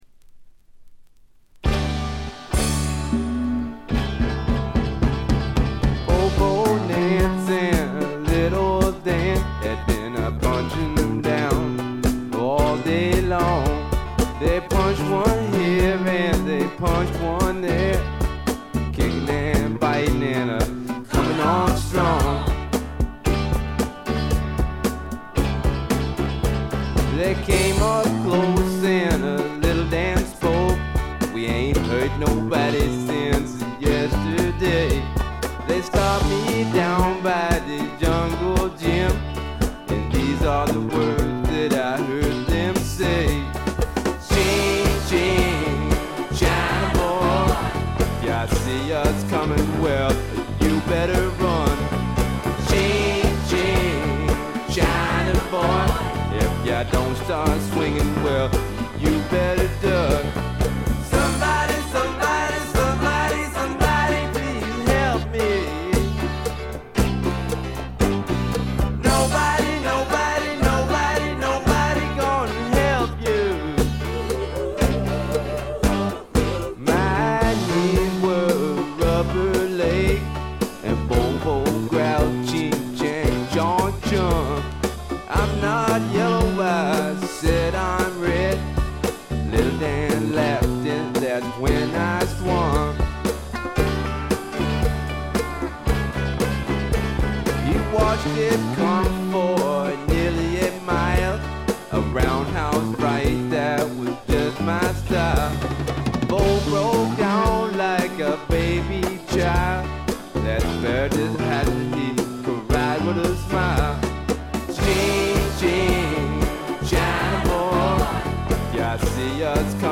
ほとんどノイズ感なし。
よりファンキーに、よりダーティーにきめていて文句無し！
試聴曲は現品からの取り込み音源です。